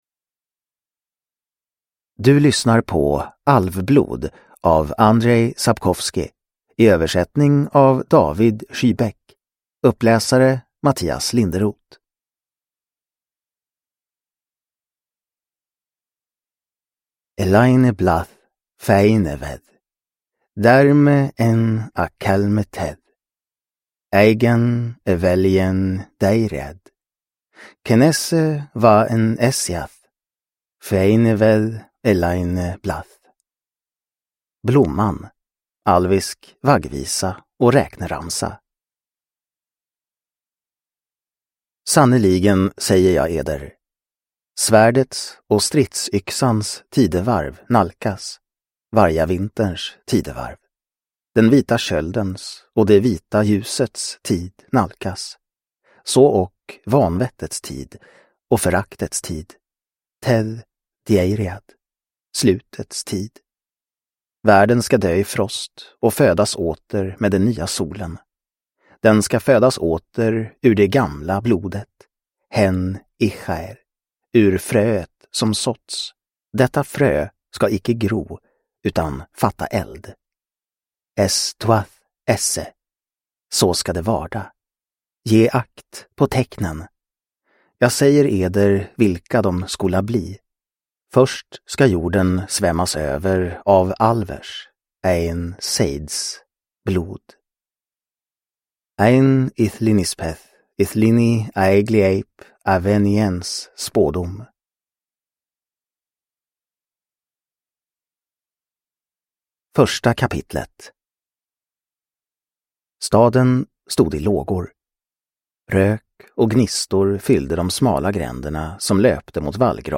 Alvblod – Ljudbok – Laddas ner